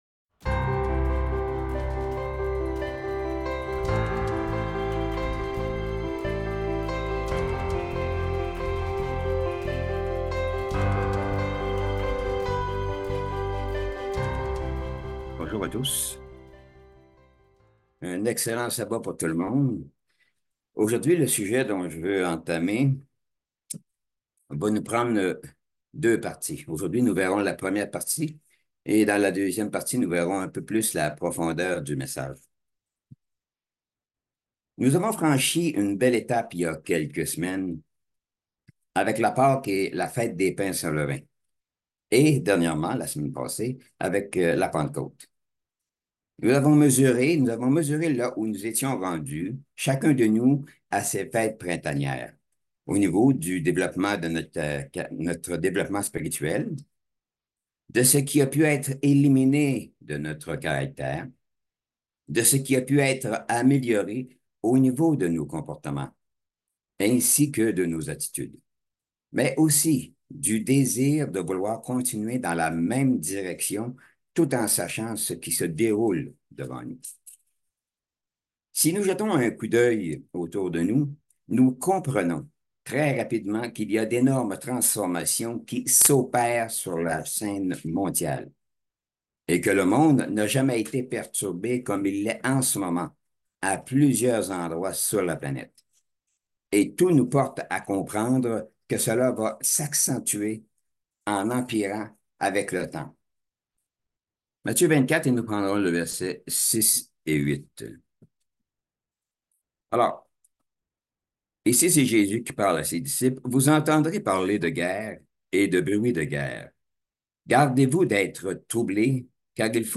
Given in Bordeaux